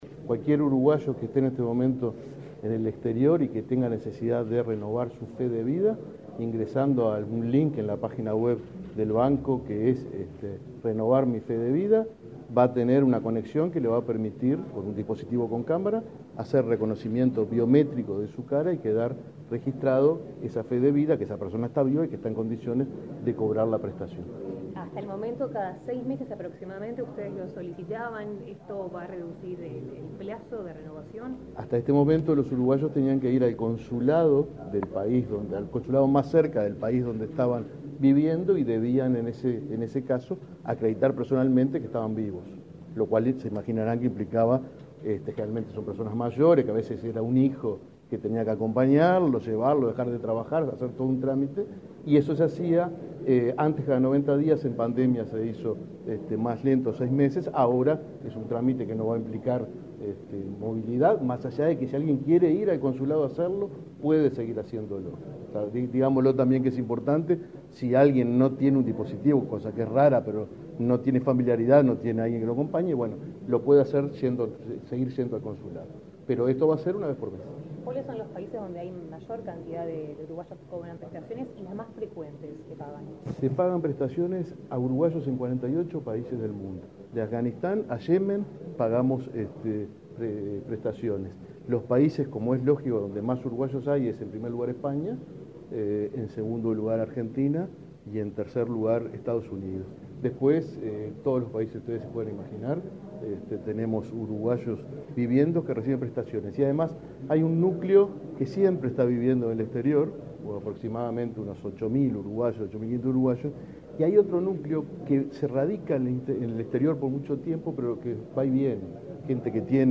Declaraciones a la prensa del presidente del BPS, Alfredo Cabrera